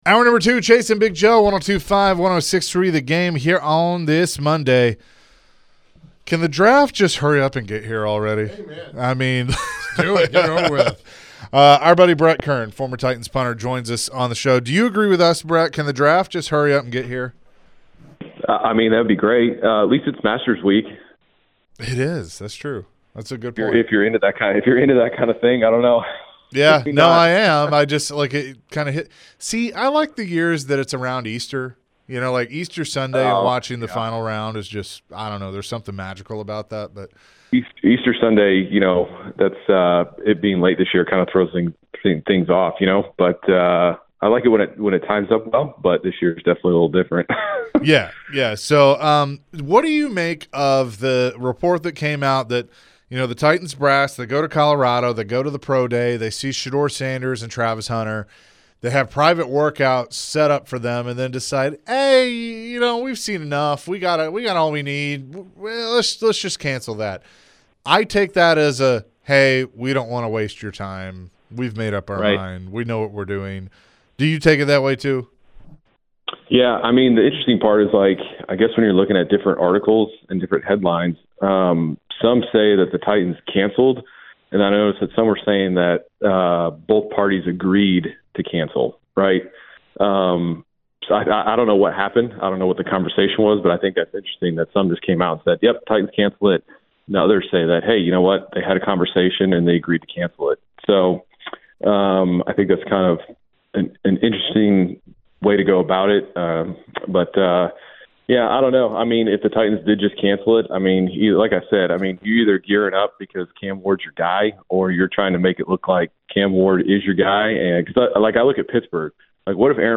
Former Titans Punter Brett Kern joined the show and shared his thoughts on the Titans and the upcoming NFL Draft. Brett also mentioned what the Titans should do with their number one overall pick and if Cam Ward is the guy for them.